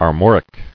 [Ar·mor·ic]